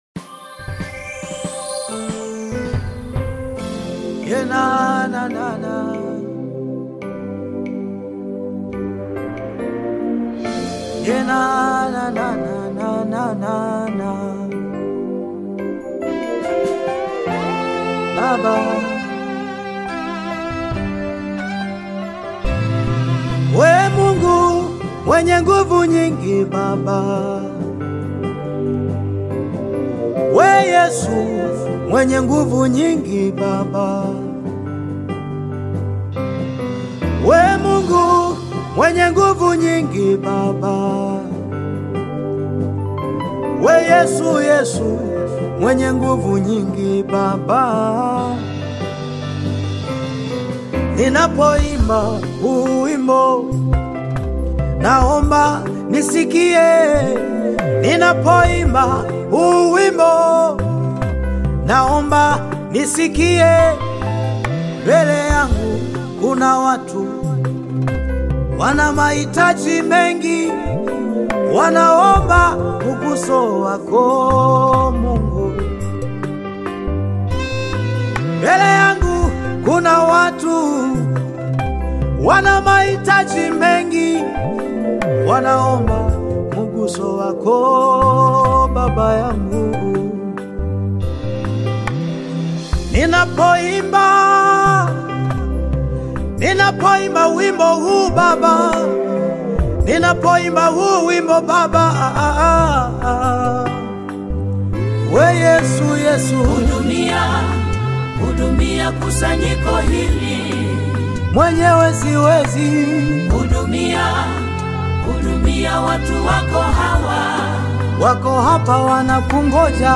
Gospel music track
Tanzanian gospel artist, singer, and songwriter
Gospel song